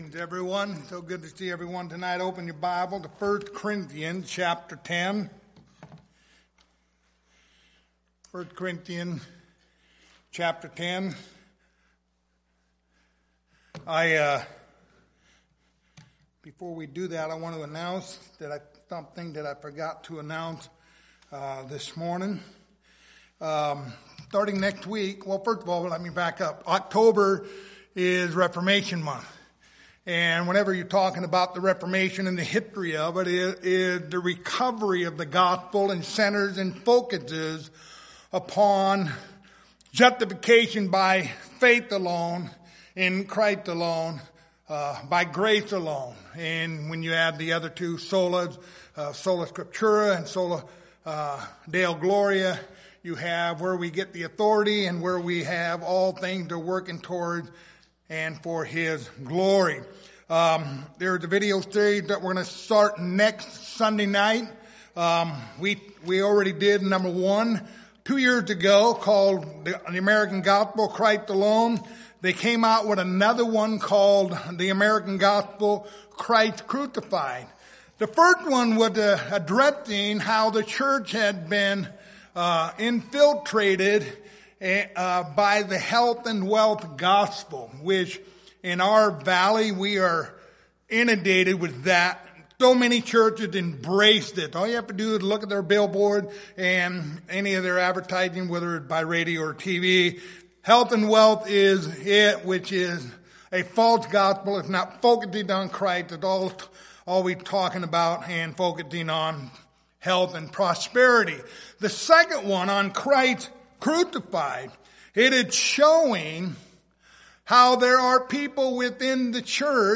Lord's Supper Passage: 1 Corinthians 10:16-22 Service Type: Lord's Supper